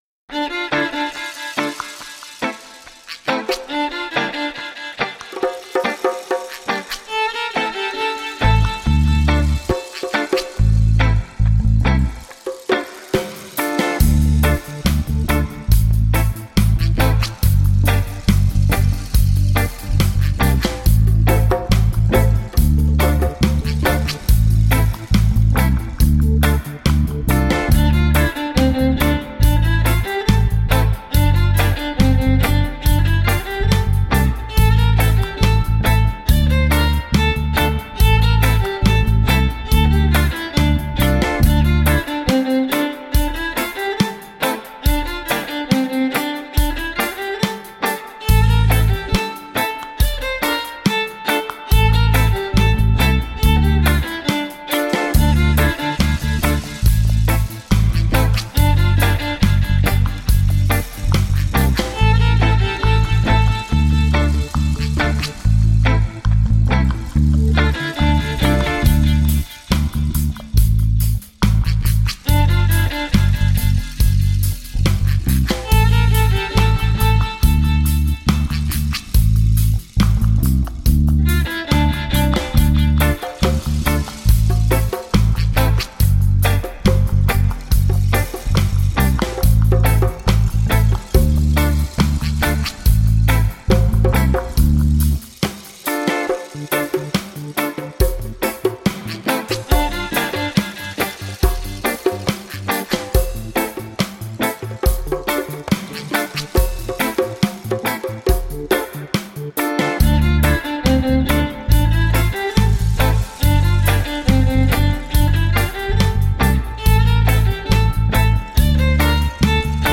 Dub, reggae, hip hop and world music from the heart.